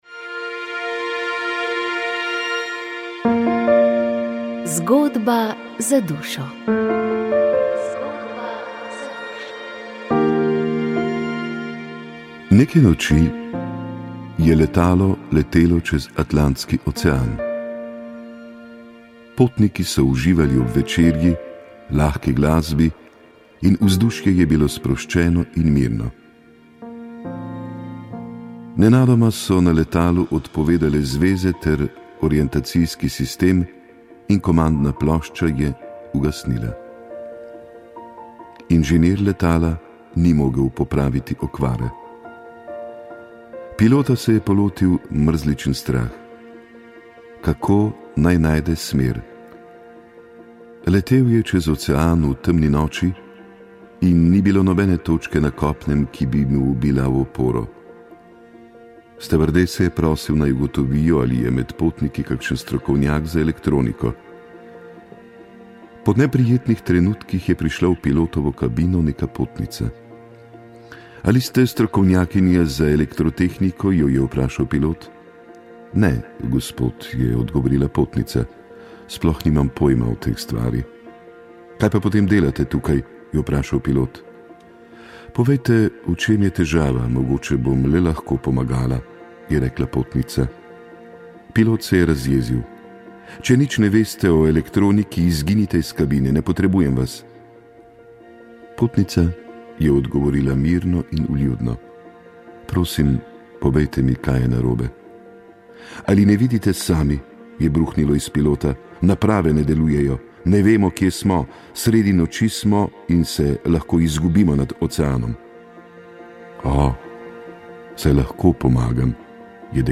Vremenska napoved 24. julij 2021